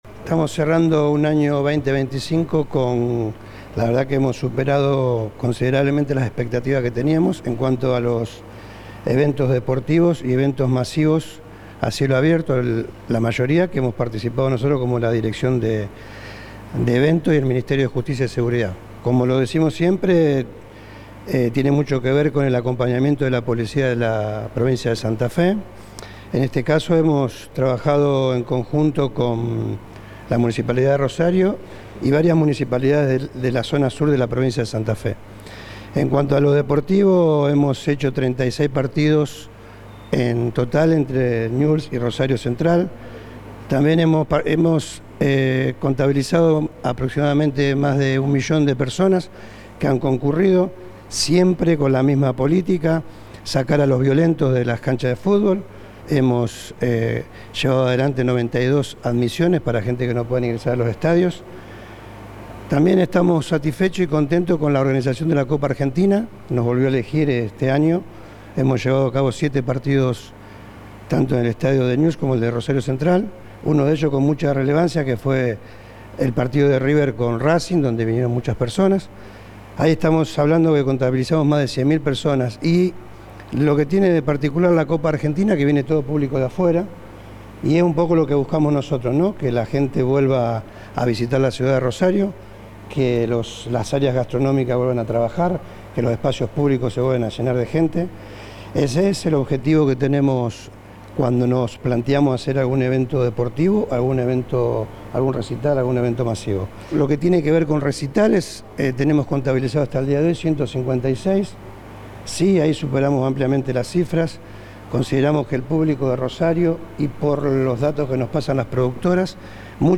Durante la presentación del informe, realizada este martes en la sede de Gobierno en Rosario, el funcionario remarcó que 2025 fue un año en el que “hemos superado ampliamente las expectativas que teníamos en relación con los eventos deportivos y los espectáculos masivos al aire libre”.
Declaraciones de Velázquez